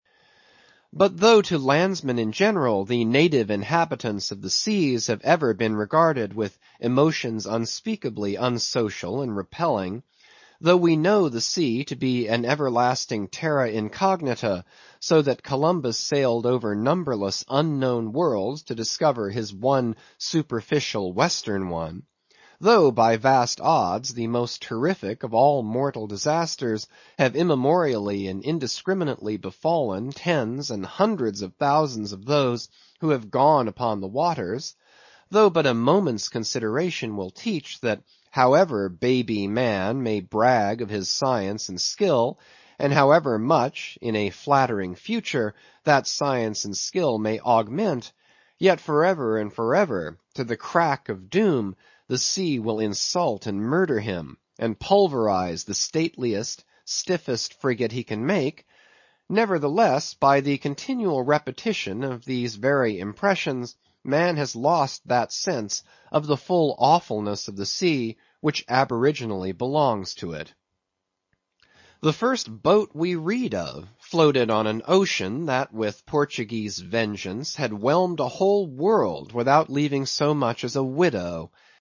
英语听书《白鲸记》第582期 听力文件下载—在线英语听力室